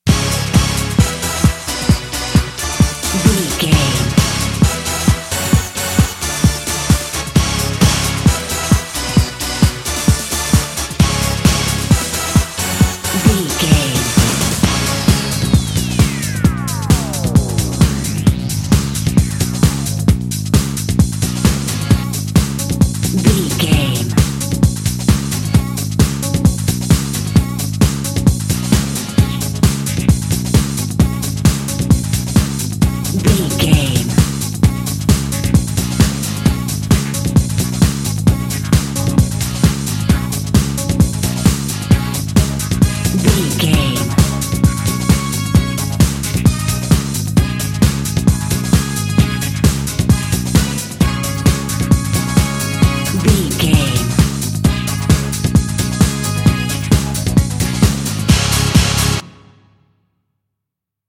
Uplifting
Ionian/Major
drum machine
synthesiser
bass guitar